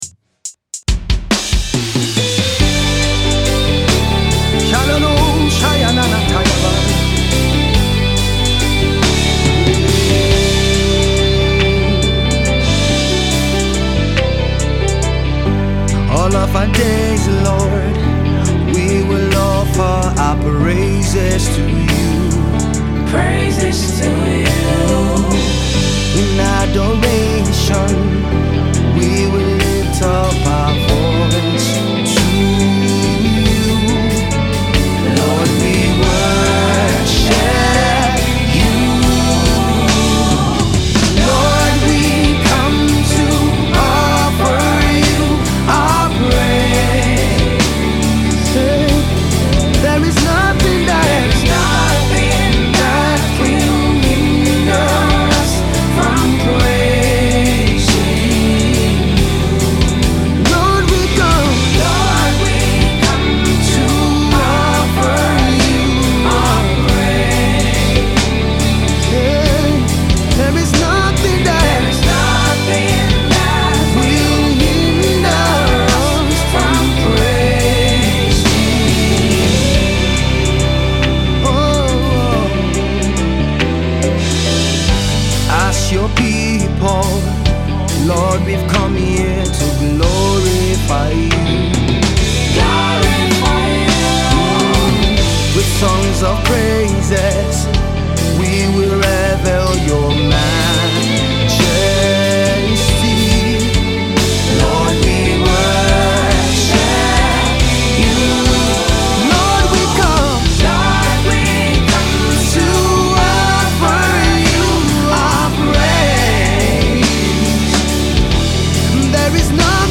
Prolific Gospel Recording Artiste and Songwriter